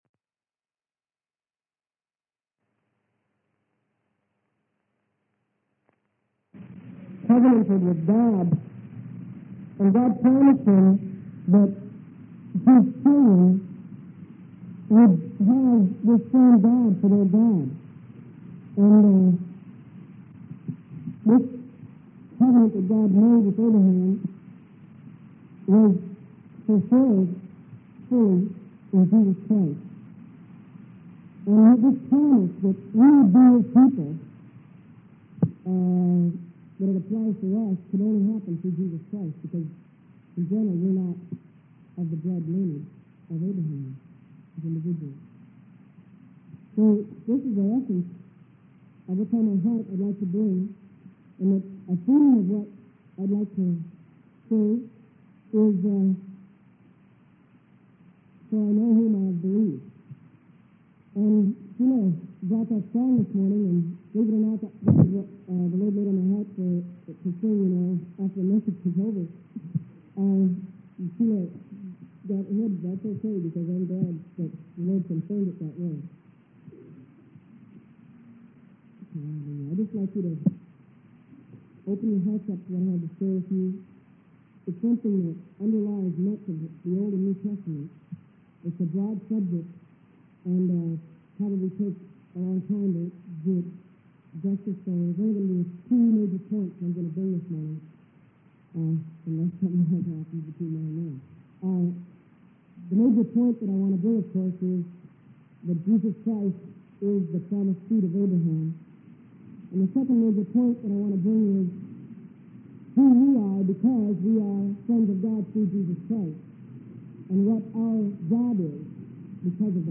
Sermon: True Seed of Abraham - Freely Given Online Library